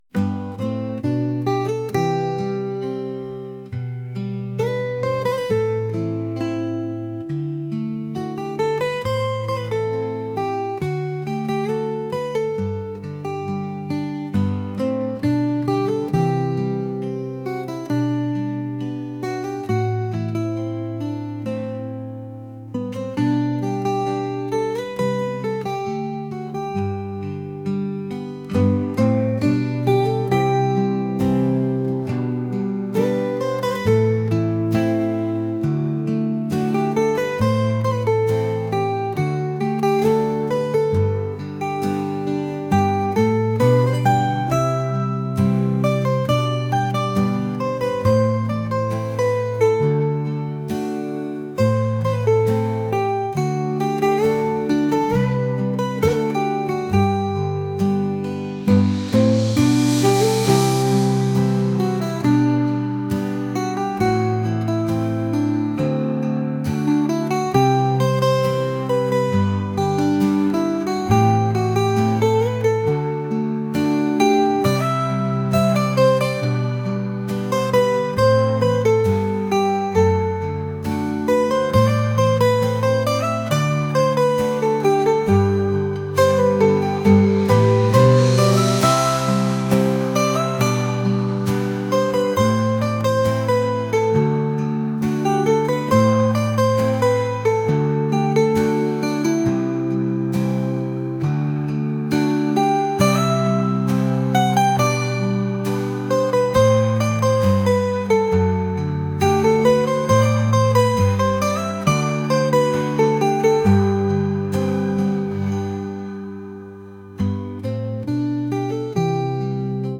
pop | acoustic | lounge